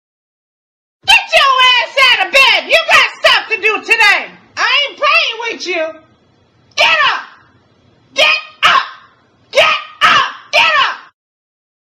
Category: Hip Hop